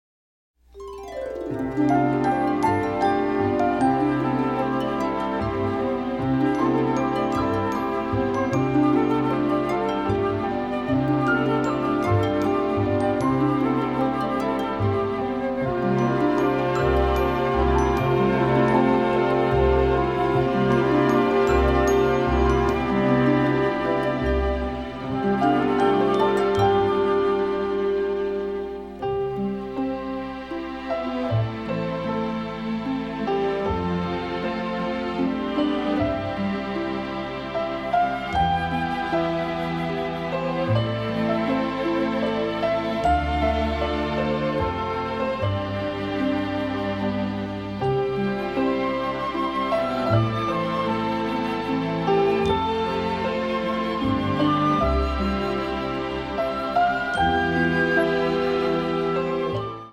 (Original Score)